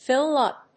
アクセントfíll úp